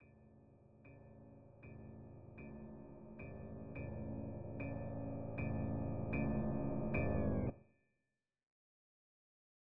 Piano Nudes Mve 2 Doubled Phrases.wav